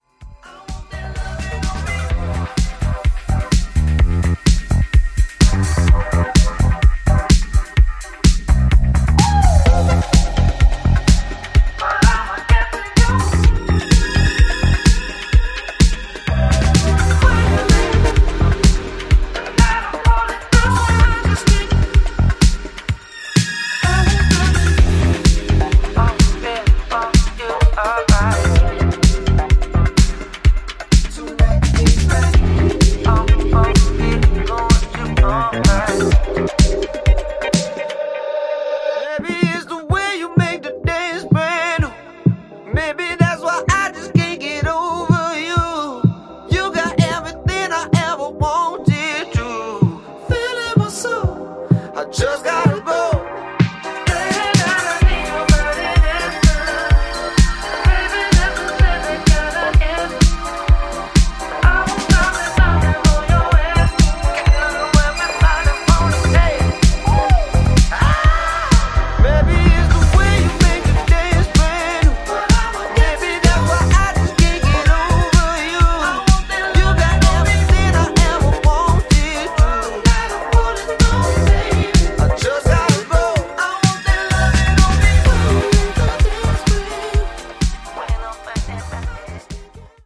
powerful disco-dub energy